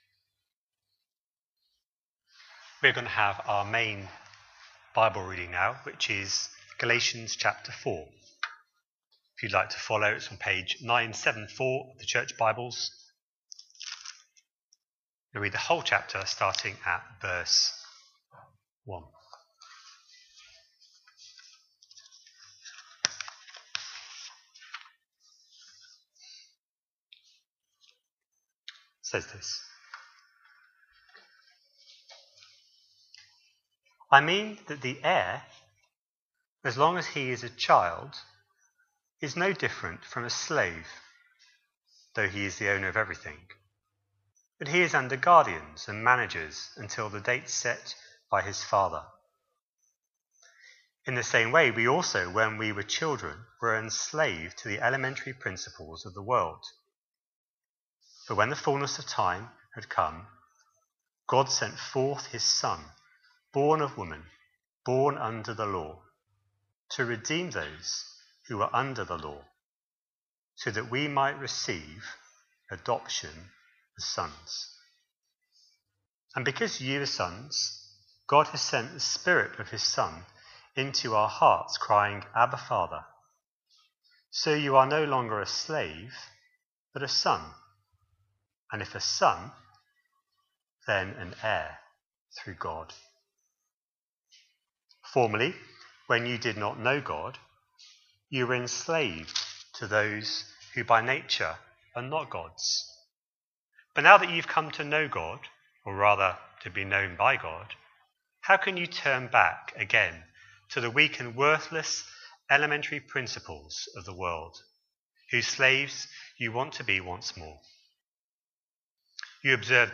A sermon preached on 22nd June, 2025, as part of our Galatians series.